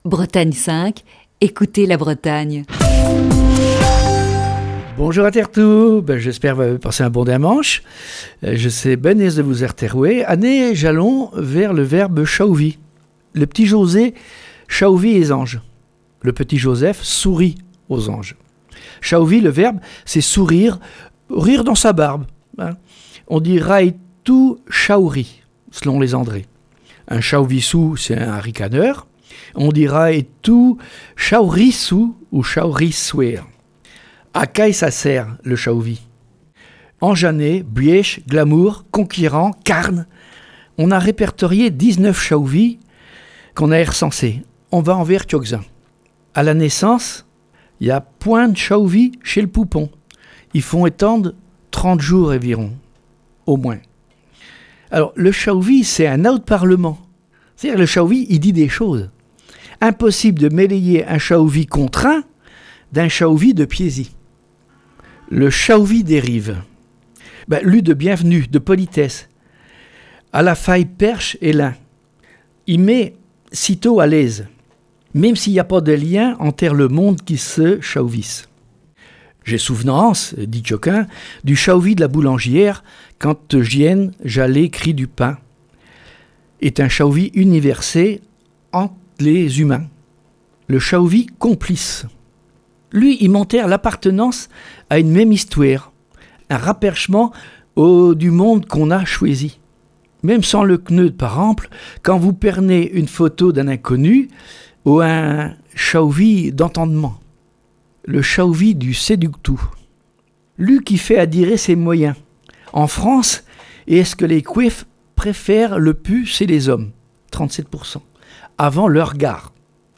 Chronique du 15 mars 2021.